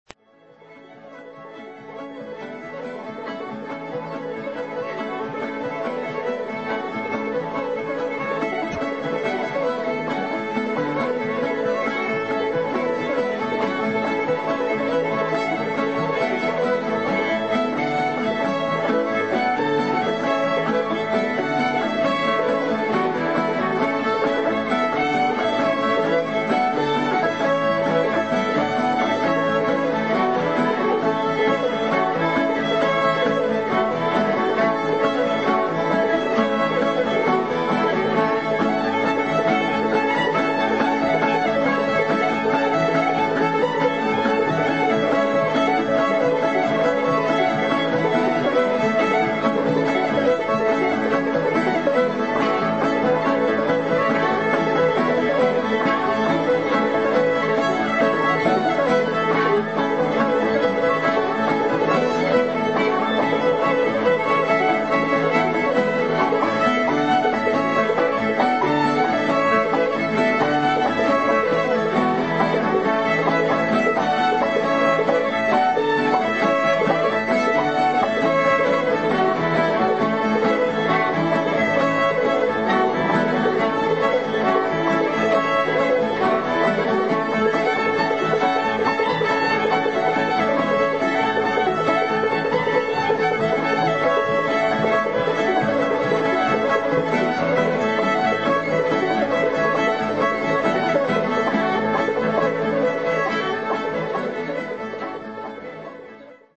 Fiddle
Banjo